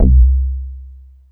Super_BassStation_04(C2).wav